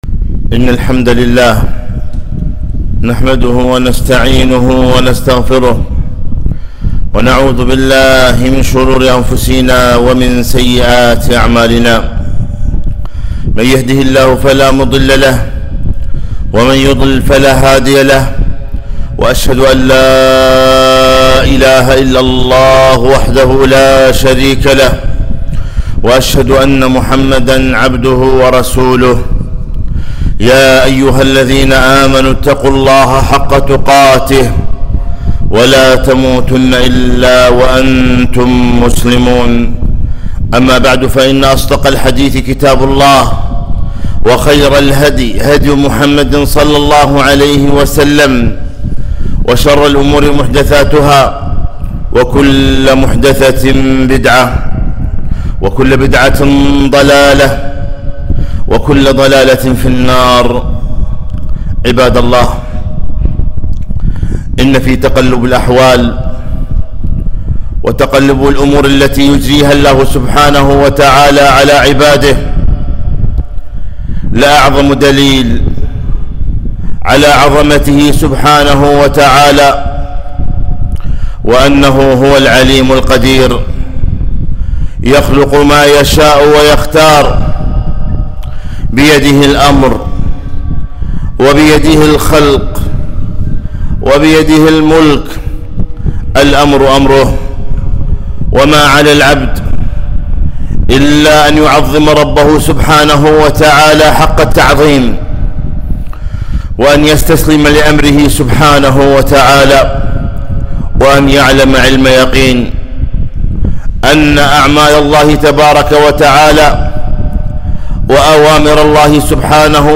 خطبة - وما نرسل بالآيات إلا تخويفا